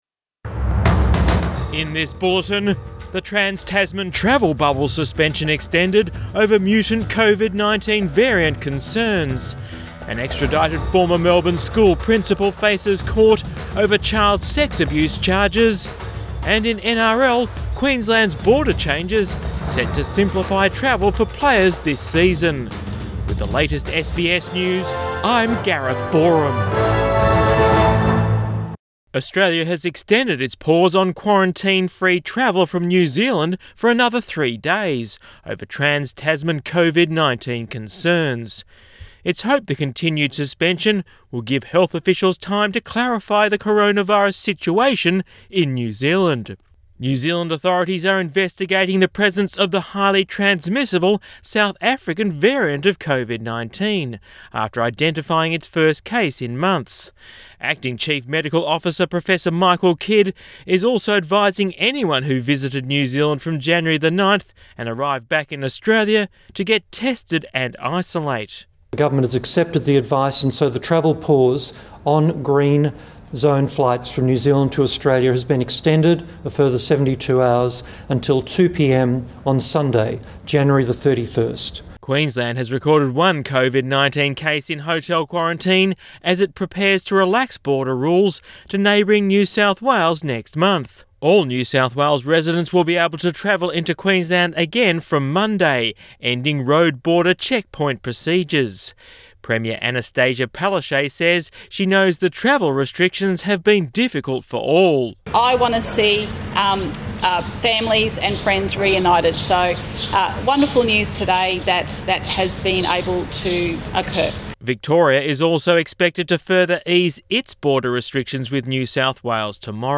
PM bulletin 28 Jan 2021